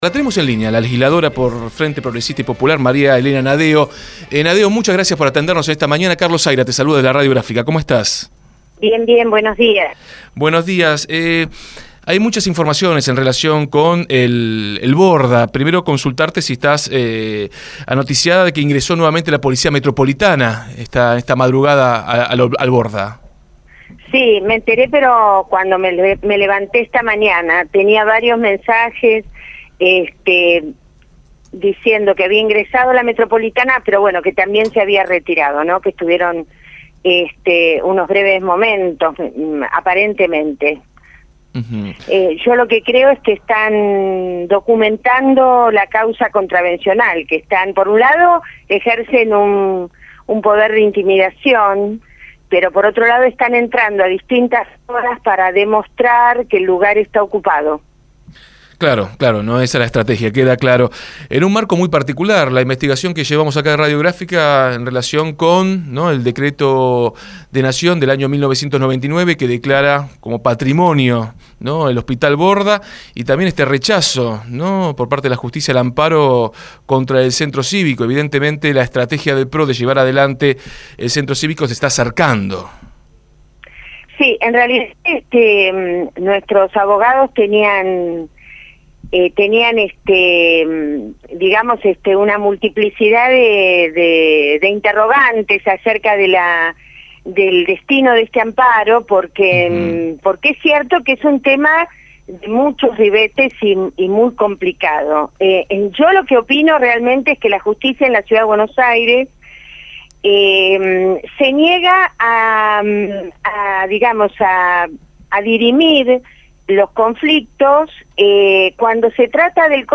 En dialogo con Desde el Barrio, la diputada porteña reconoció que tras interiorizarse en la norma sancionada por el entonces presidente Menem, gracias a la difusión del dato realizada por nuestra emisora, que se trata de un tema con muchos ribetes y muy complicado.
07-09-12 NADDEO MARIA ELENA, LEGISLADORA FRENTE PROGRESISTA Y POPULAR, DESDE EL BARRIO